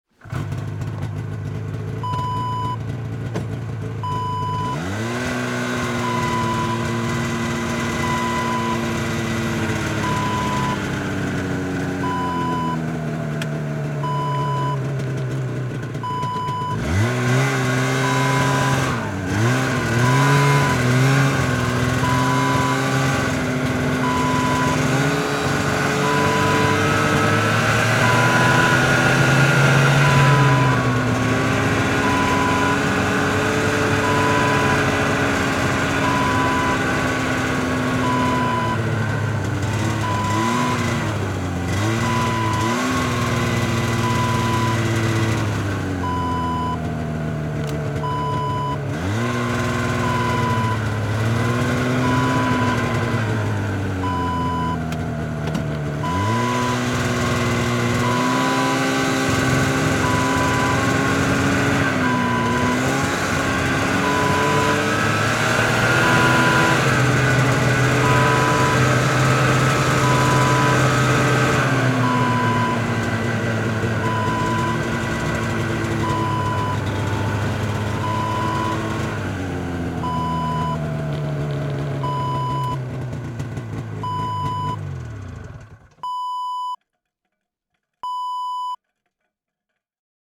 Snowmobile: Riding Wav Sound Effect #1
Description: A person riding a snowmobile
Properties: 48.000 kHz 24-bit Stereo
A beep sound is embedded in the audio preview file but it is not present in the high resolution downloadable wav file.
snowmobile-ride-preview-1.mp3